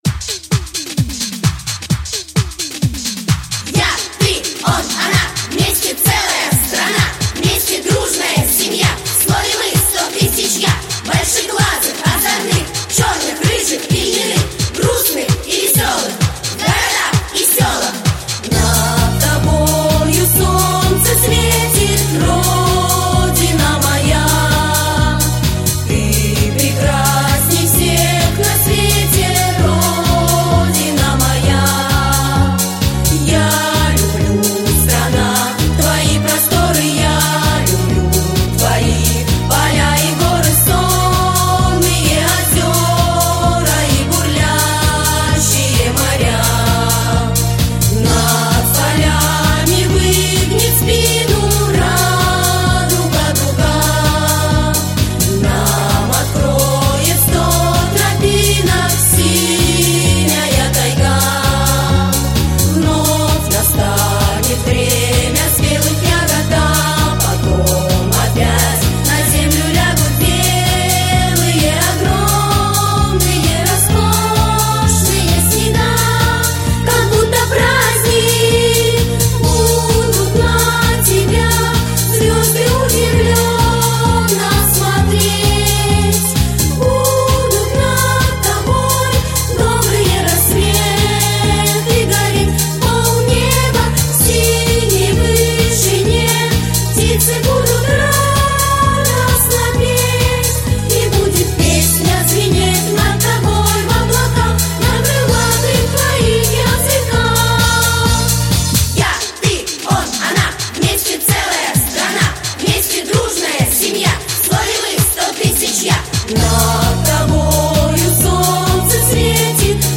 детская песня про Россию